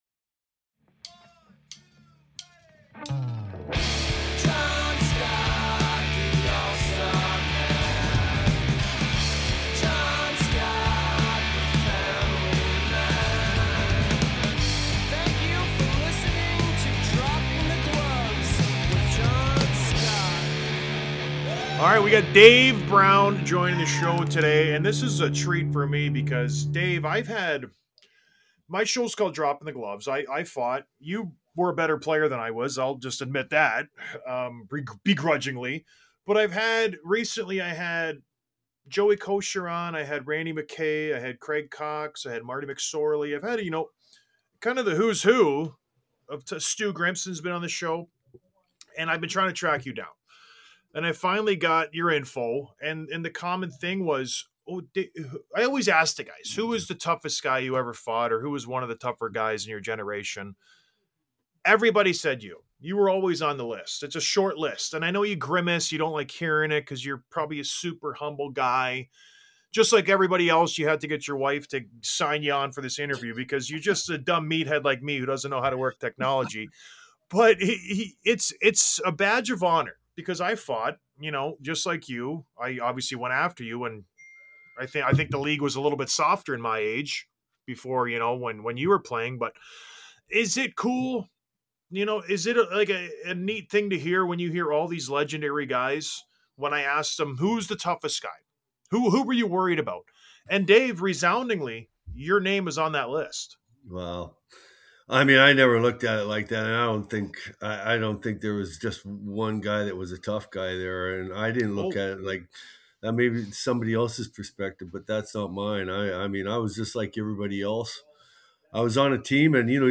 Interview w/ Dave Brown